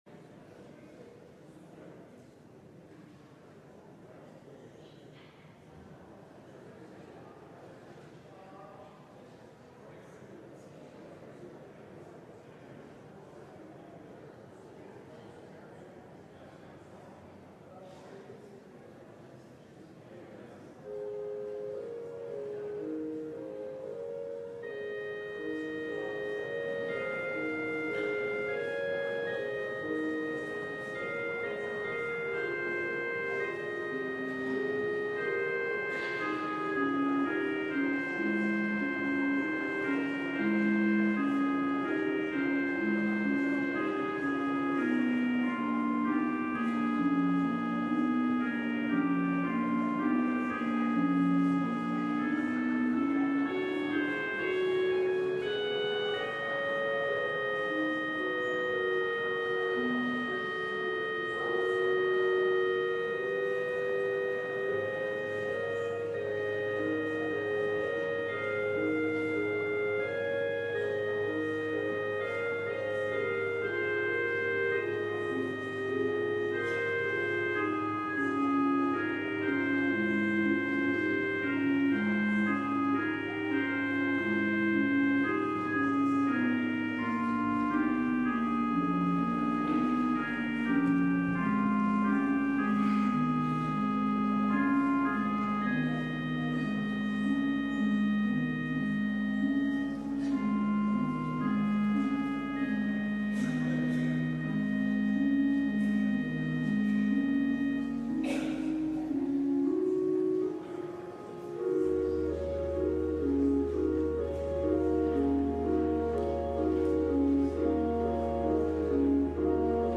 LIVE Morning Worship Service - God’s Covenant with Abraham